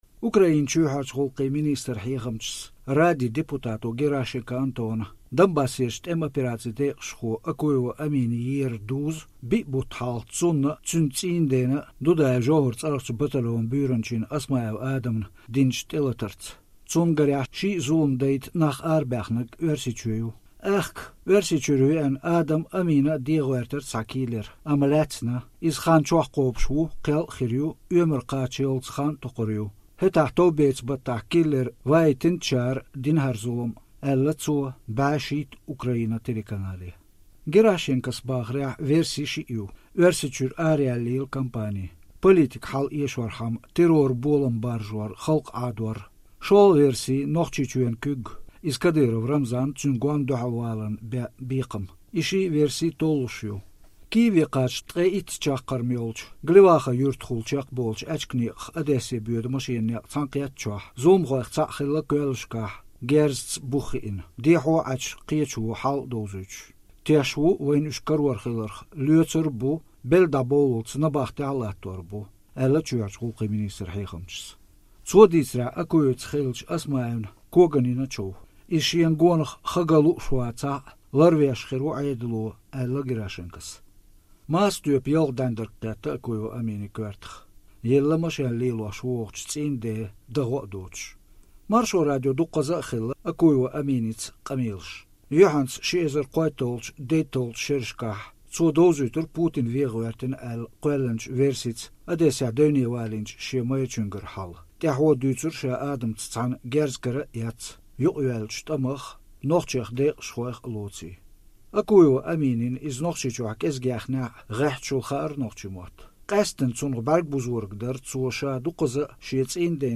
Хетарехь, уггаре а билгалдериг дара цуьнца хиллачу Маршо Радион интервьюшкахь иза цIийннана муьлхха а борша стаг хьега мегар долуш тешаме а, майрачух шен са дIалур долуш а хилар.